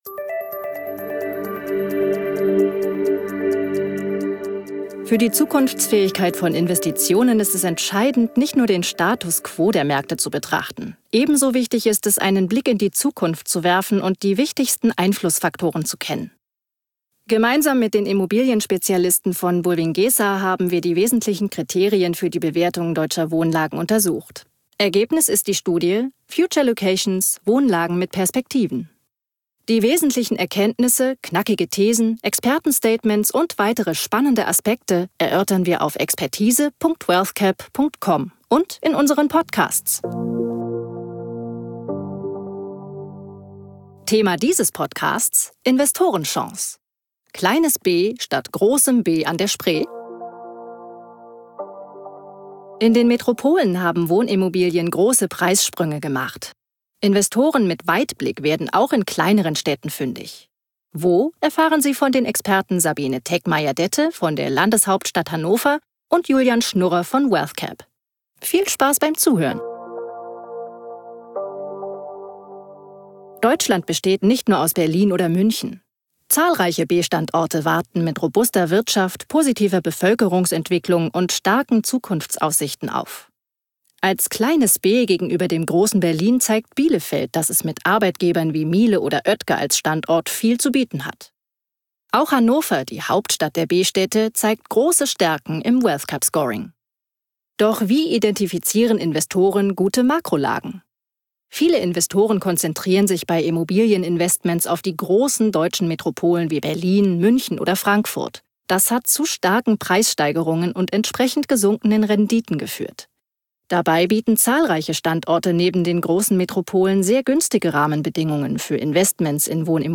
Angereichert werden die Podcasts mit Expertenstatements und O-Töne.